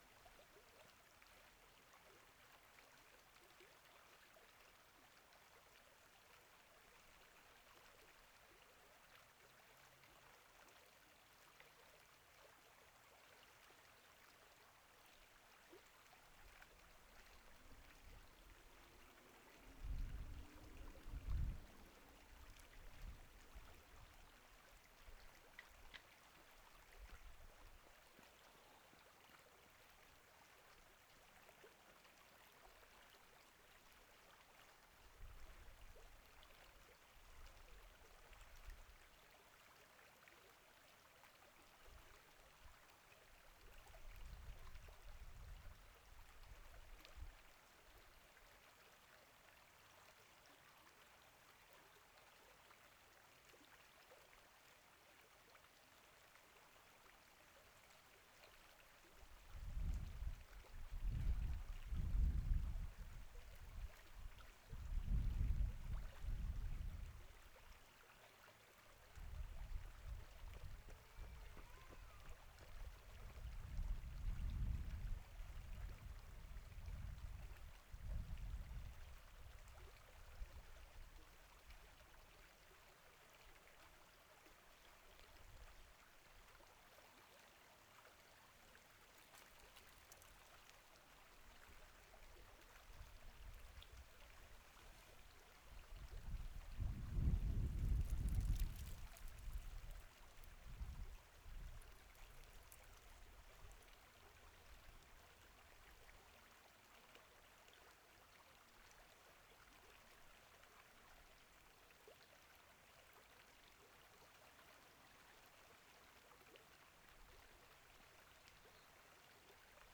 「黒目川の流れ」　2020年5月24日
どちらのマイクも、風による吹かれ音が入ります。
右側には西武線が通っています。
レコーダー／PCM-D10